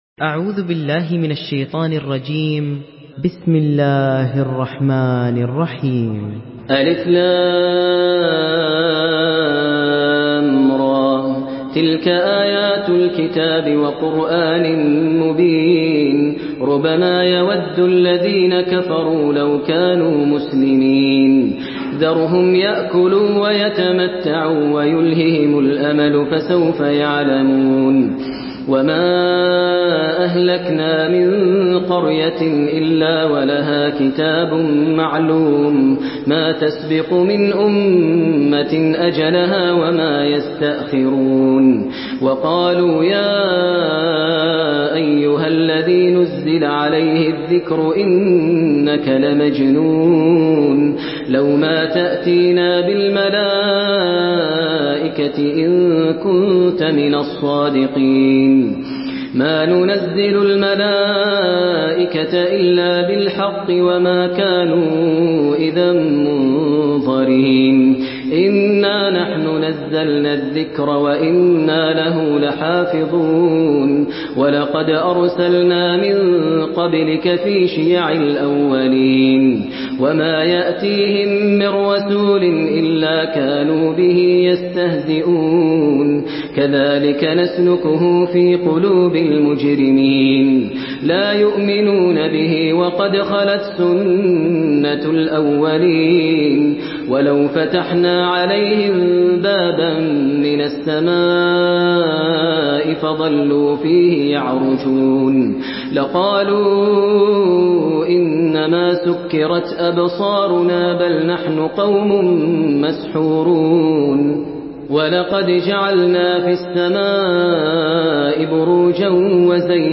تحميل سورة الحجر بصوت ماهر المعيقلي
مرتل حفص عن عاصم